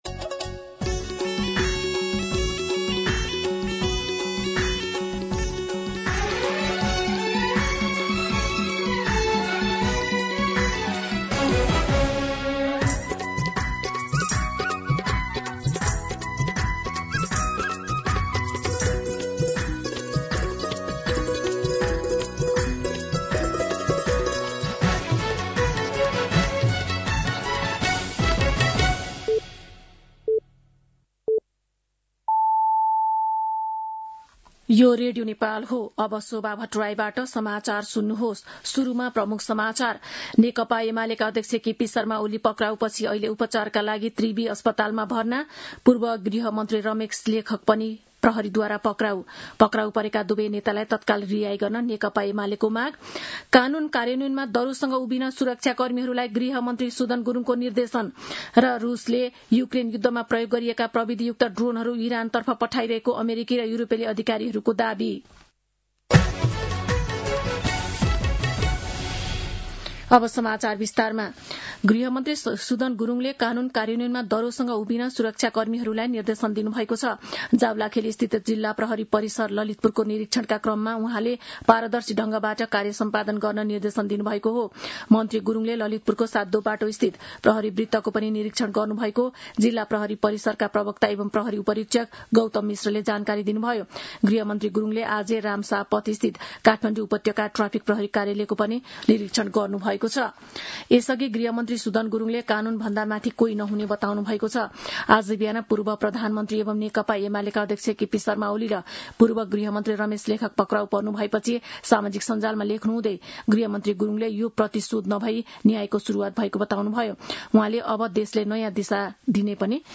दिउँसो ३ बजेको नेपाली समाचार : १४ चैत , २०८२